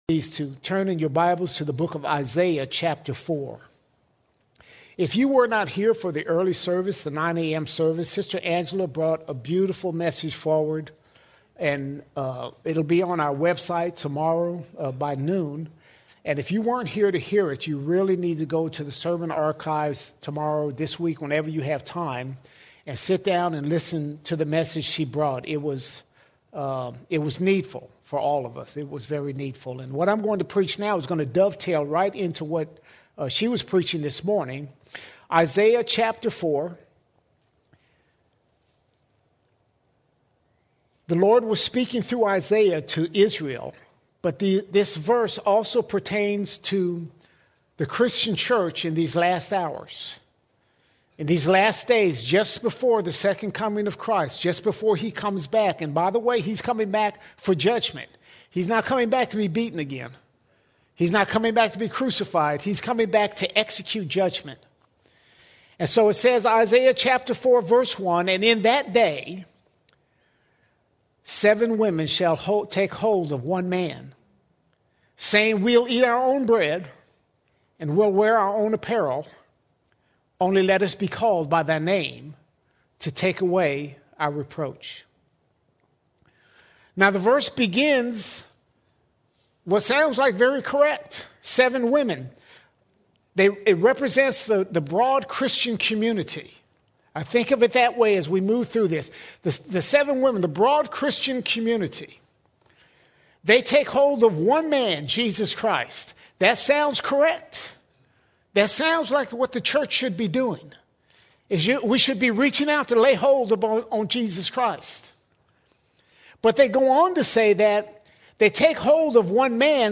14 December 2025 Series: Sunday Sermons All Sermons The Wedding Garment The Wedding Garment No matter who we are or how hard we try, our own works are like filthy rags.